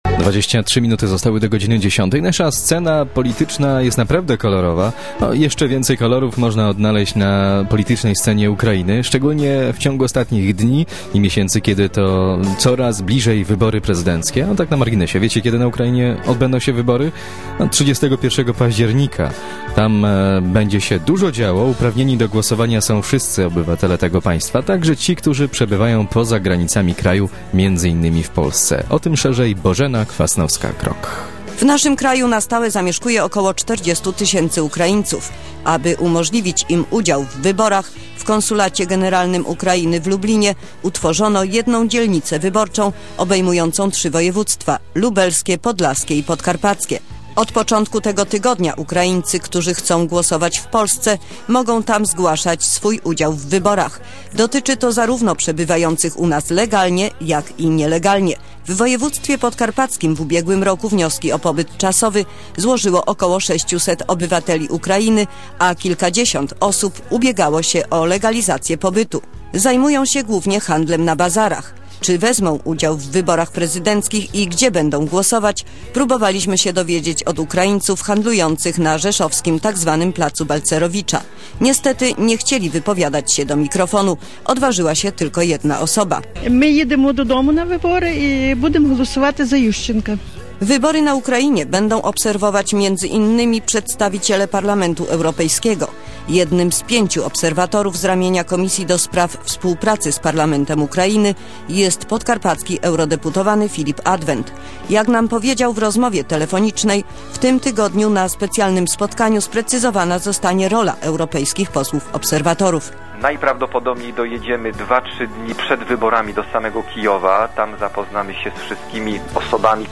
Audycja w radiu FAN FM - 14 października 2004 (MP3 - 1,59 MB)